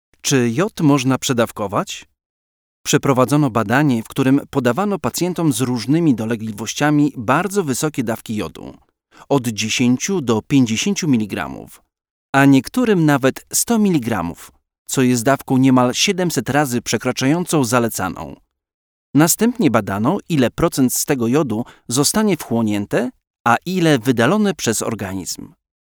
Comercial, Amable, Cálida, Suave, Empresarial
Explicador
At the same time, with very good diction.